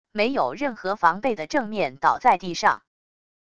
没有任何防备的正面倒在地上wav音频